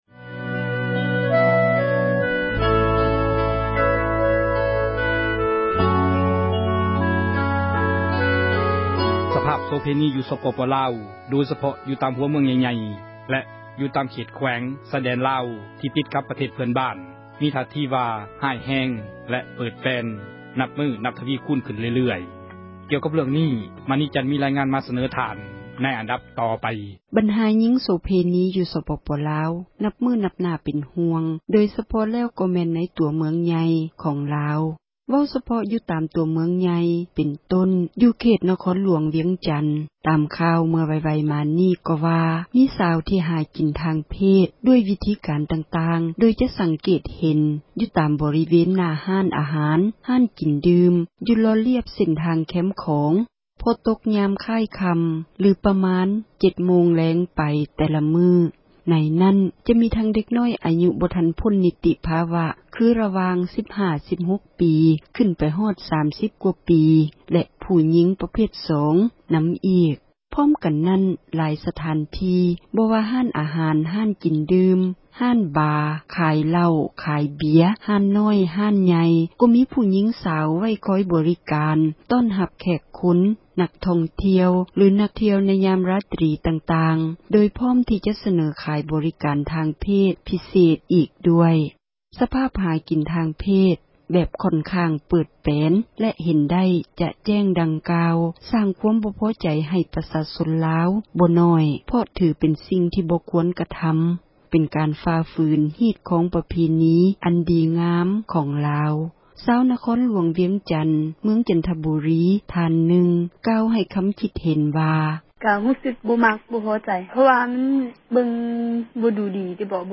ສະພາບການເຣື້ອງ ໂສເພນີ ຢູ່ ສປປລາວ ແລະຕາມ ເຂດຊາຍແດນ — ຂ່າວລາວ ວິທຍຸເອເຊັຽເສຣີ ພາສາລາວ